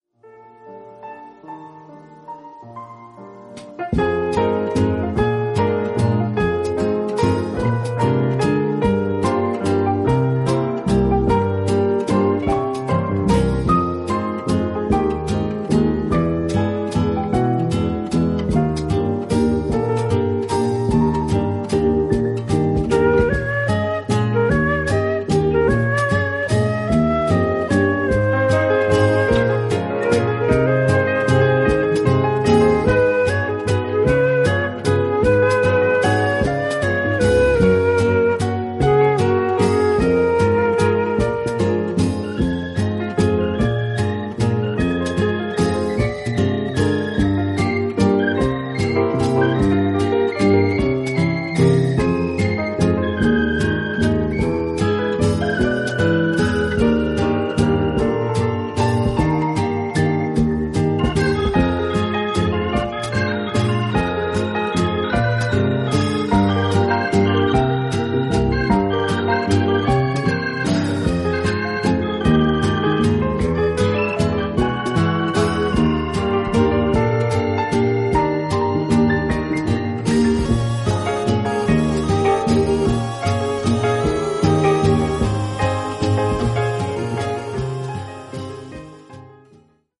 こちらのアルバムは本名でのスタジオセッション